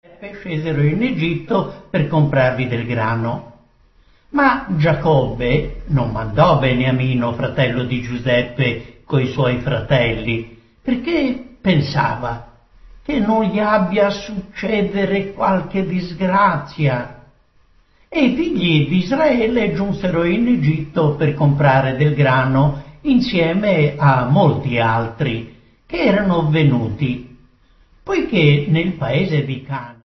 Due CD contenenti l’intera Bibbia letta dal narratore